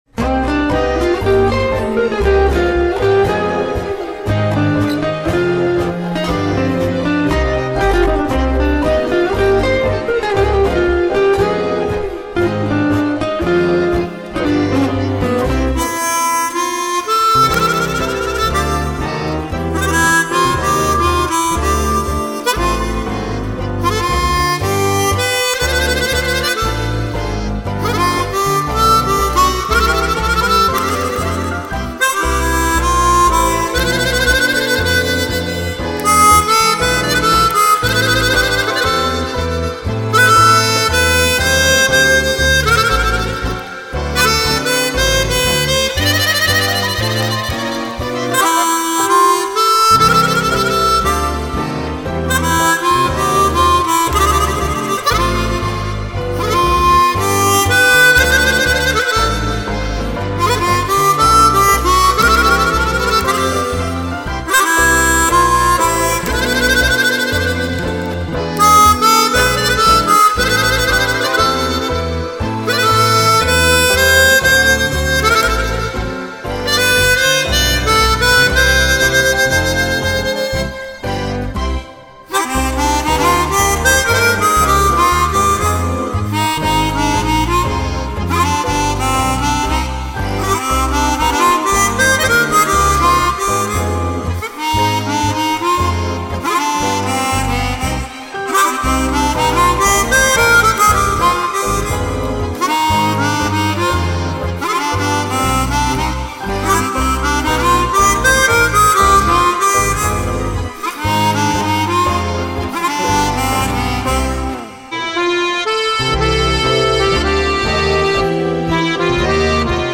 version harmonica